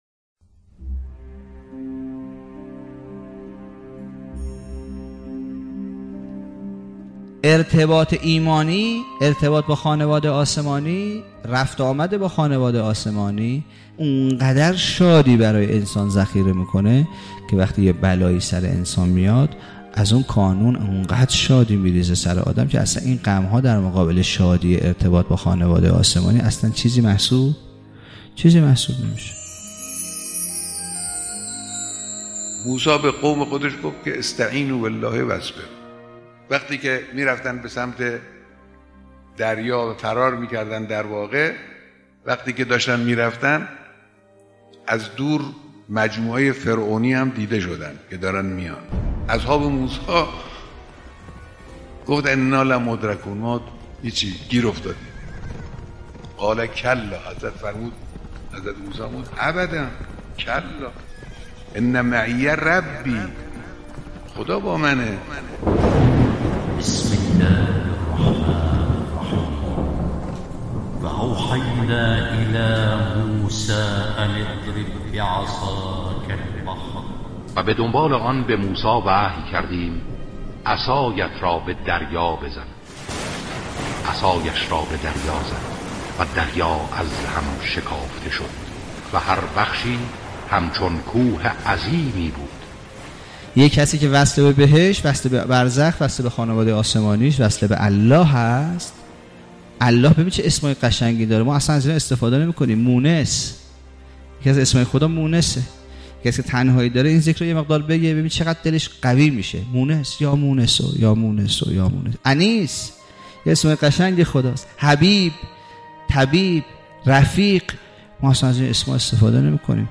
کارگاه تفکر